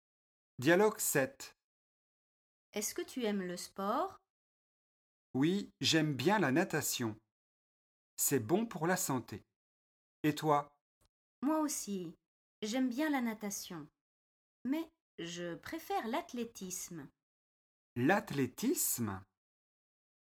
8 dialogues en français FLE très courts et très faciles pour débutant (A1).
Dialogue 7